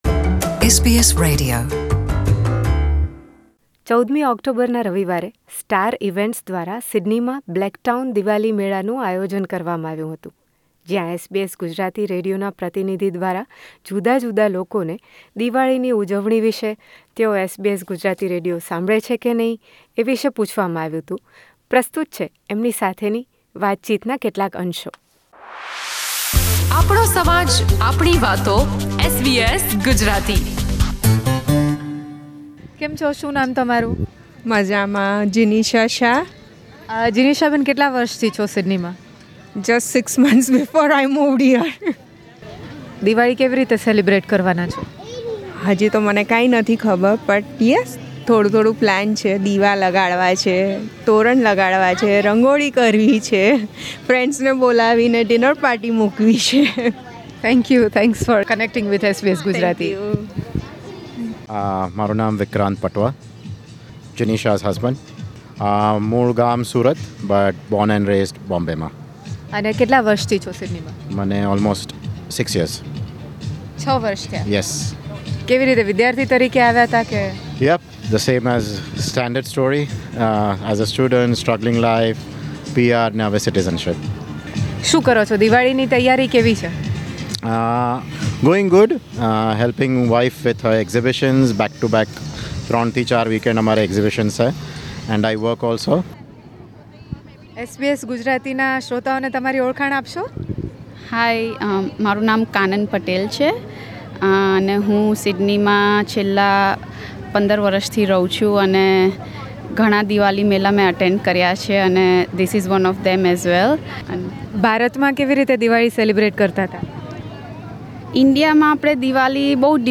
પ્રસ્તુત છે ત્યાંના સાંસ્કૃતિક કાર્યક્રમમાં ભાગ લેનારાં બાળકોનાં માતા- પિતા અને મેળાના મુલાકાતીઓ સાથે દિવાળીની એમની તૈયારીઓ વિષે અમે કરેલી વાતચીત.